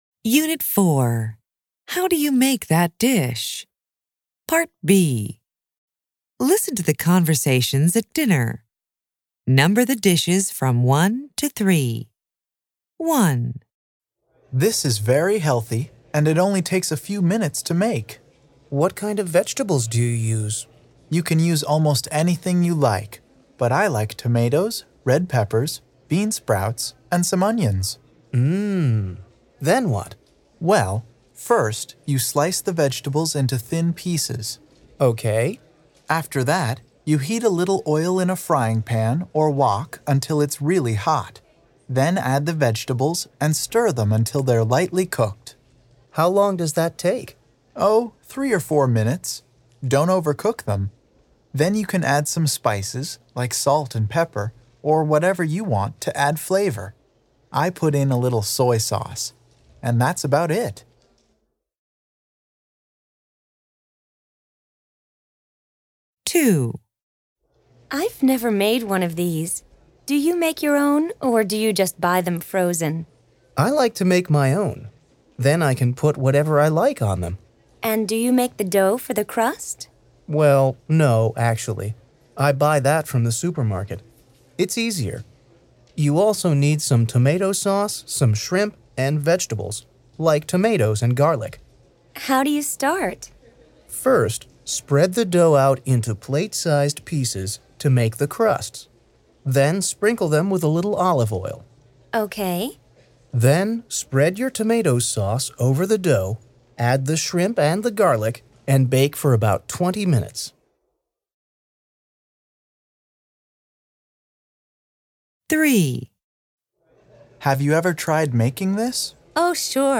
American English
interchange4-level2-unit4-listening-audio.mp3